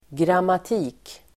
Ladda ner uttalet
Uttal: [gramat'i:k]